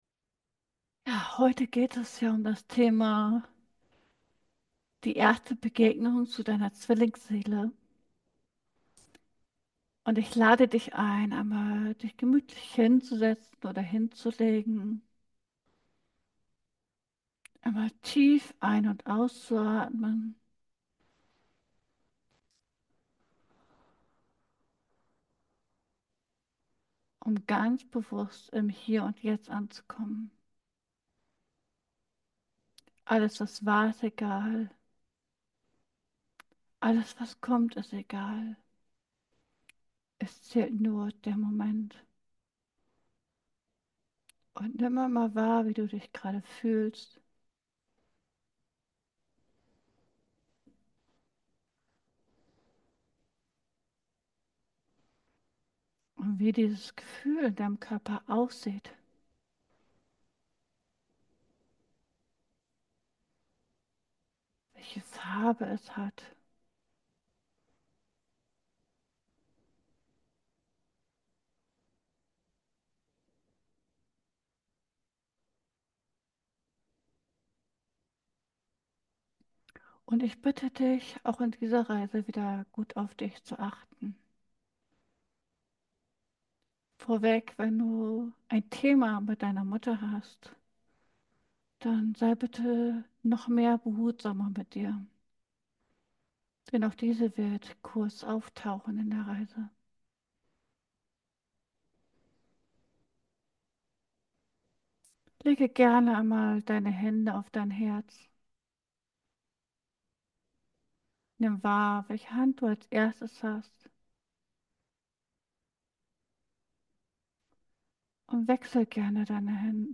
In diesem beruhigenden Video nehmen wir dich mit auf eine spirituelle Reise zu deiner Zwillingsseele. Diese geführte Meditation lädt dich ein, dich in einem geschützten Raum zu entspannen und die Verbindung zu deiner inneren Kraft und Liebe zu entdecken.